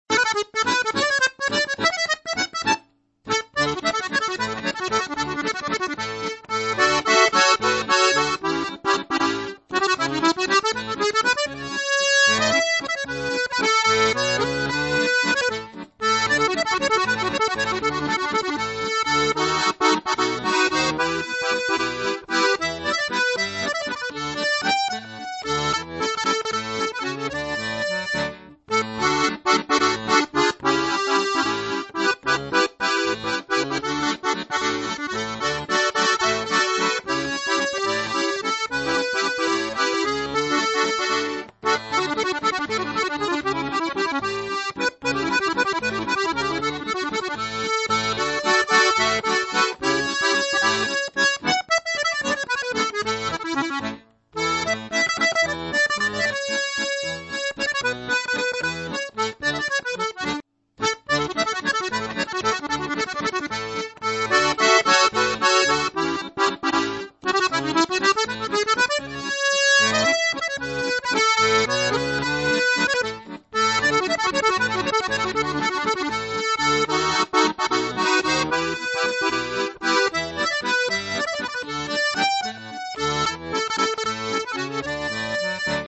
Rock, jazz, polcas, bailes de Auvernia (bourrées), valses, java, tango, madison, fox-trot, gaita, folk, musicas Tex-Mex, cajun y zydéco, musicas traditionnales, swing, gitanos y swing-musette, etc; el accordeón se presta de buen grado a todas las clases musicales.
valse musette hace clic